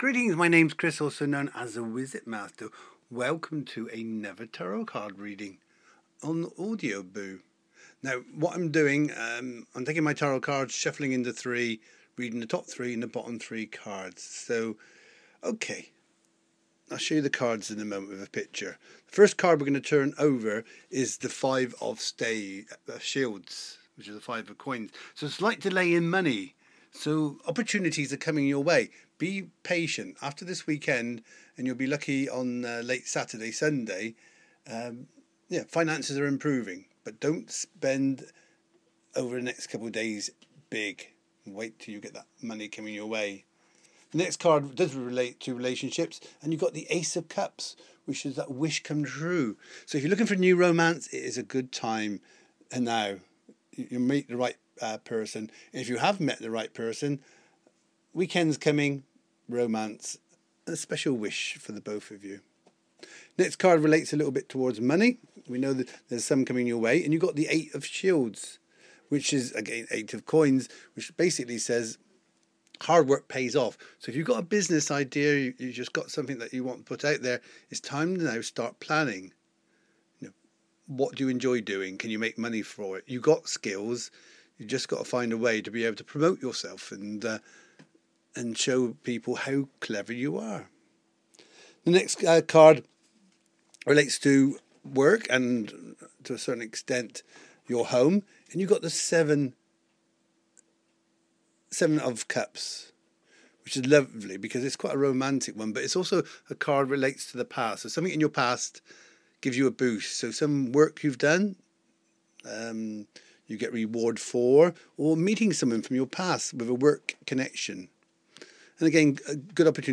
Tarot reading for Friday